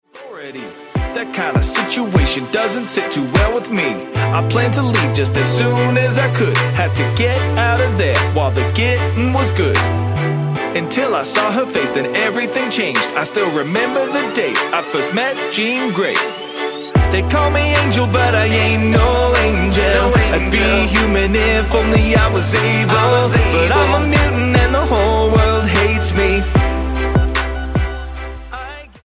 STYLE: Hip-Hop
Sung hooks and some clever production